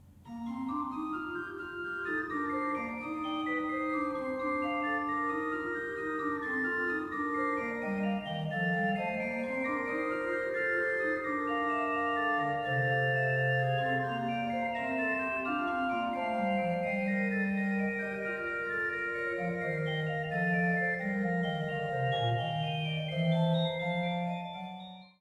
Bourdon 16'
Montre 8'
Soubasse 16'